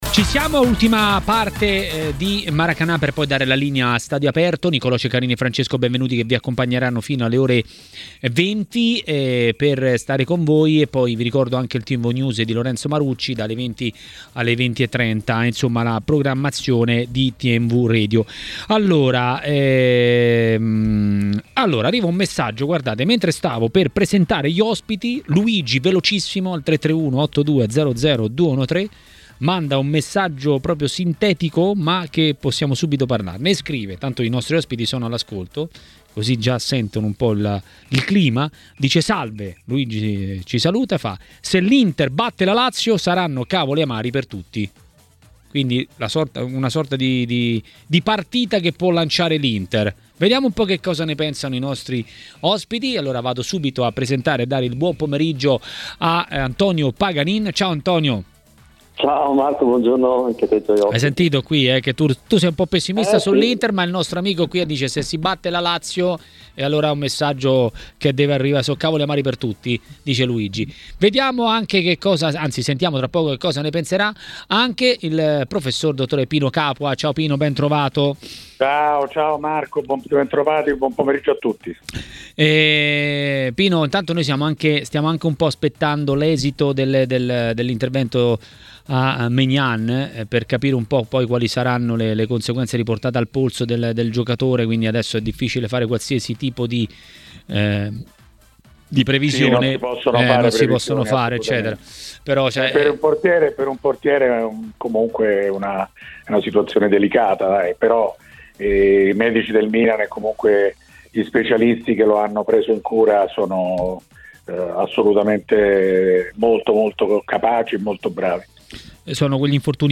Per parlare di Lazio-Inter a Maracanà, nel pomeriggio di TMW Radio, è intervenuto l'ex calciatore Antonio Paganin.